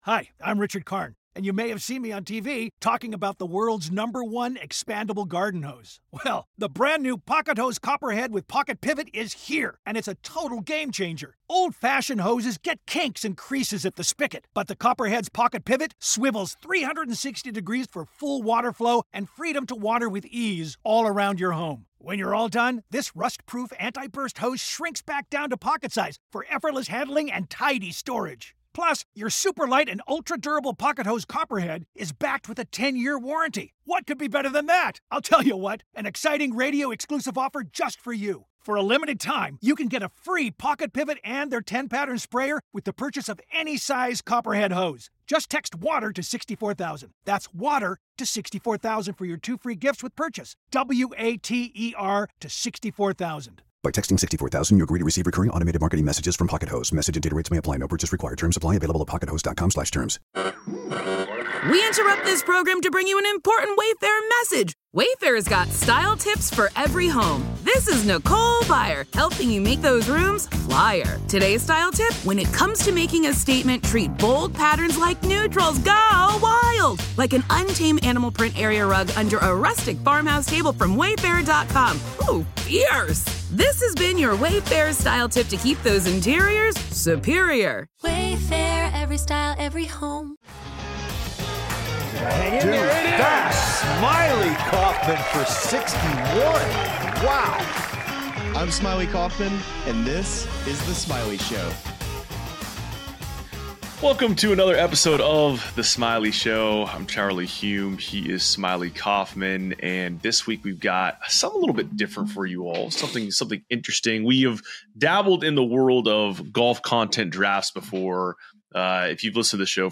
GOLF DRAFT: Projecting the Stars of the Next Decade + Ben Crenshaw Interview